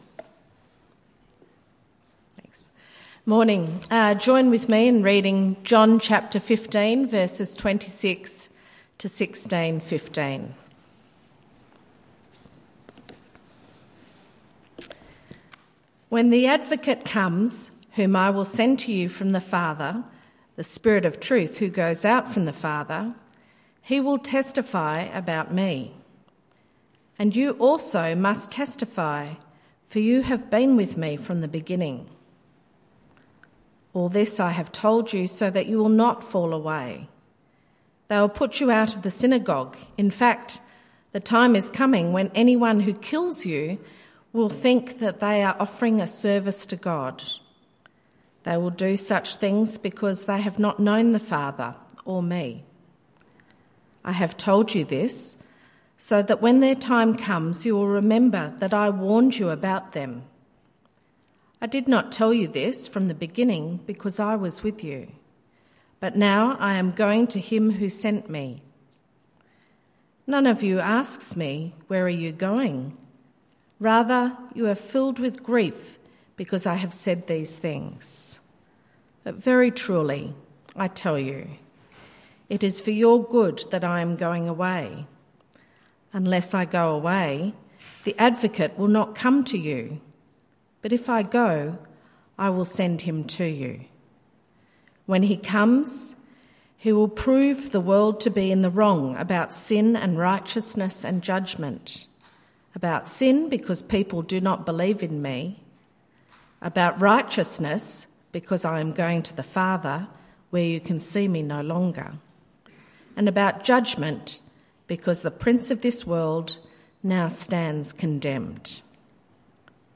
St Barnabas Sermons Podcast - The Spirit Jesus sends | Free Listening on Podbean App